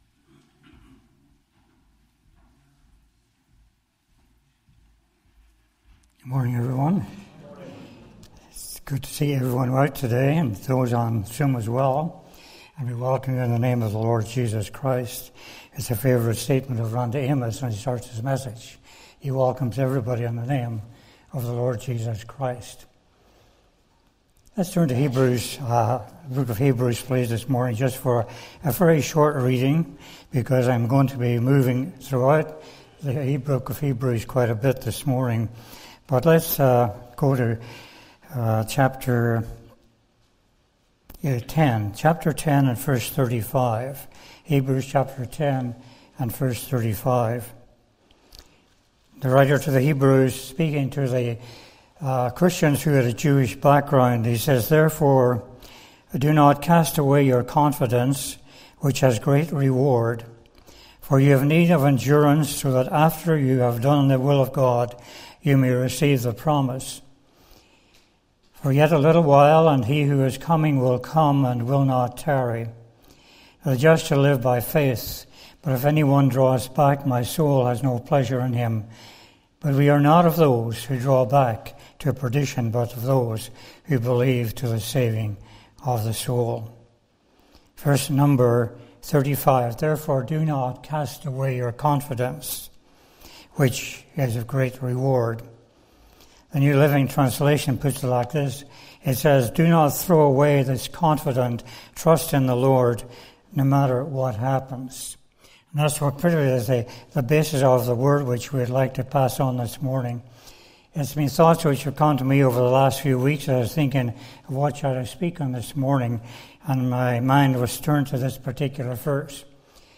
Heb 10:35 Service Type: Family Bible Hour A message of encouragement during these troubled times.